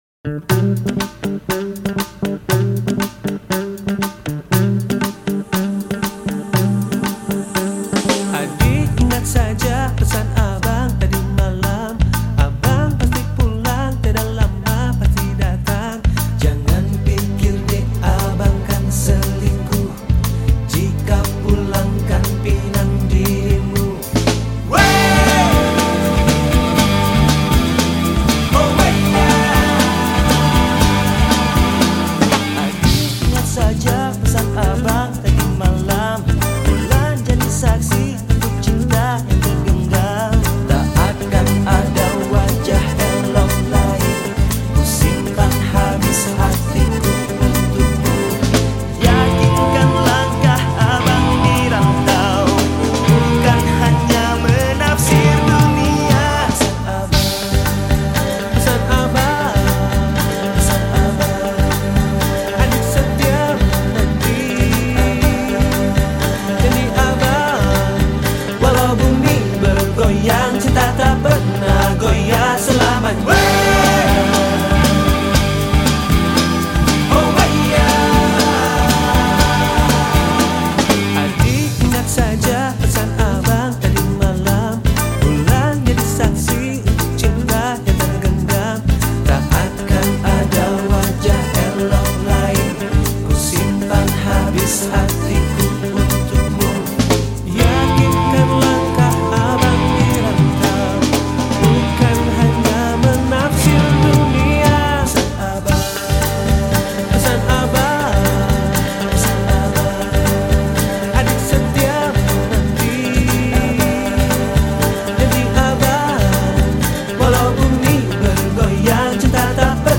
Genre Musik                                : Jazz dan Pop